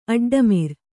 ♪ aḍḍamir